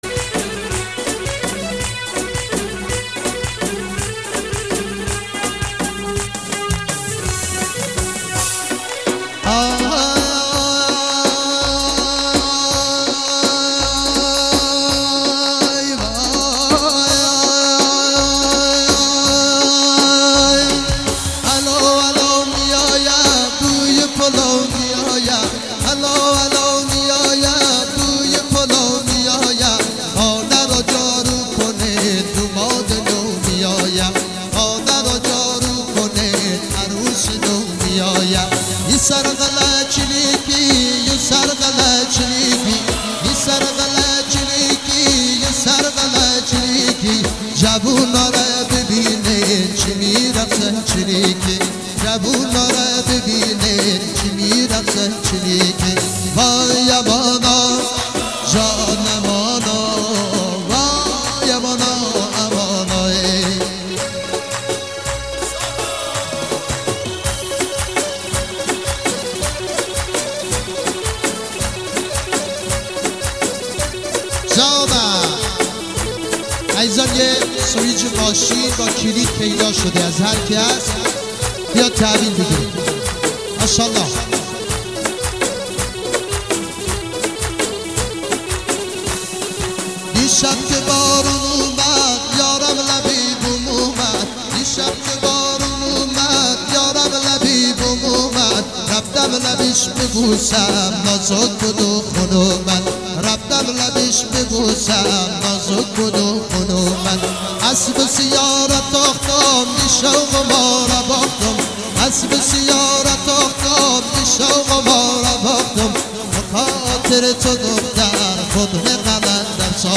آهنگ محلی شاد